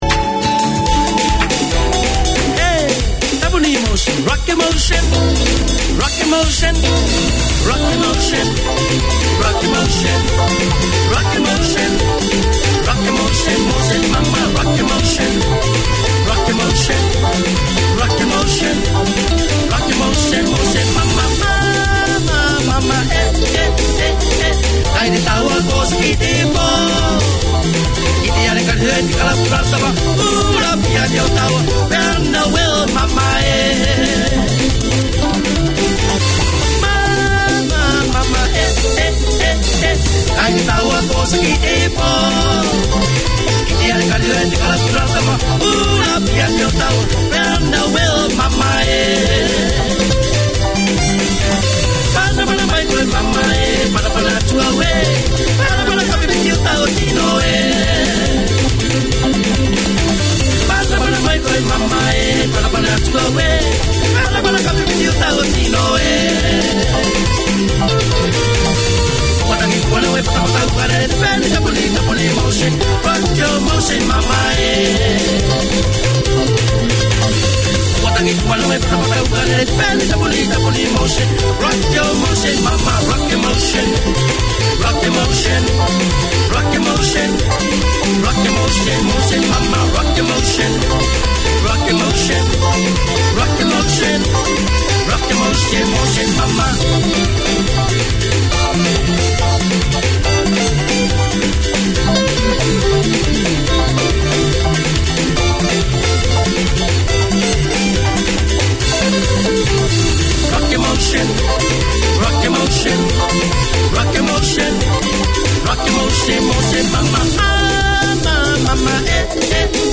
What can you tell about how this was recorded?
An outreach of the Pacific Islands Health and Welfare project under the auspices of the Auckland Health Board, Cook Islands Health is the half hour each week that keeps you in touch with health news, with interviews, information, community updates.